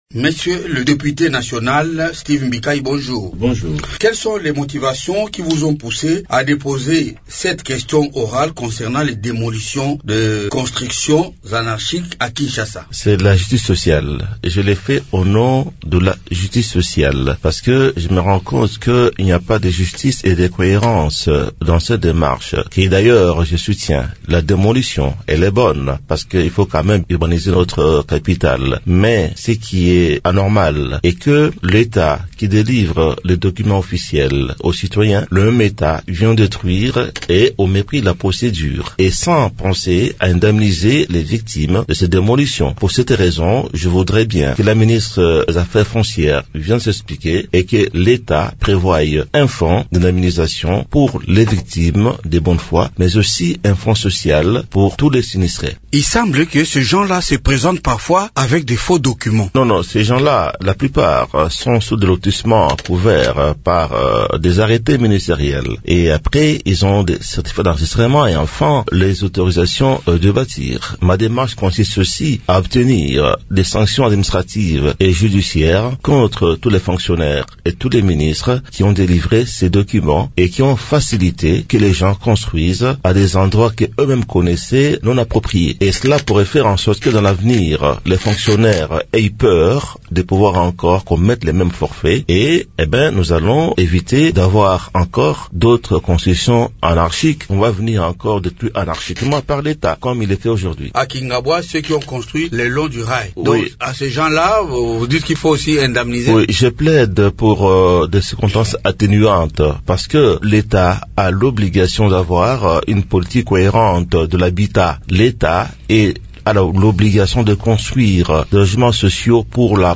« Il est inadmissible que des familles investissent dans des constructions validées par des titres officiels, pour ensuite voir leurs maisons détruites sans recours ni compensation », a déclaré Steve Mbikayi, invité de Radio Okapi ce vendredi 17 octobre.